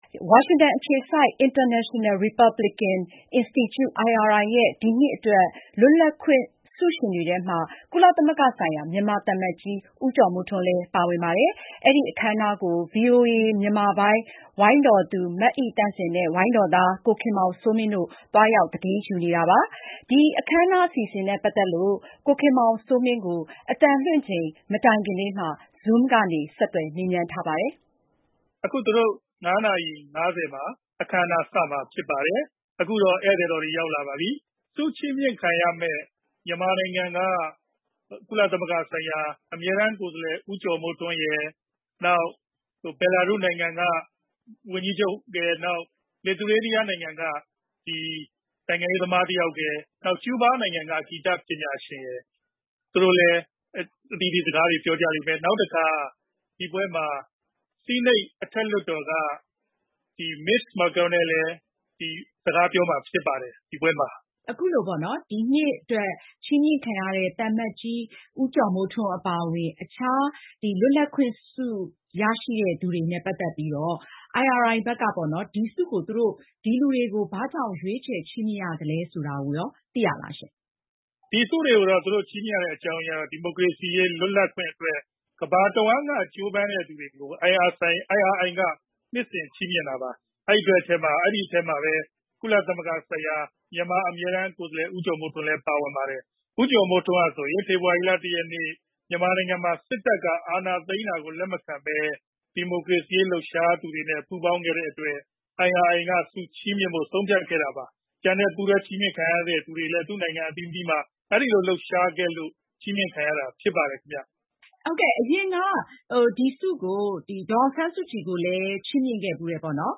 Zoom ကနေ ဆက်သွယ်မေးမြန်းထားပါတယ်။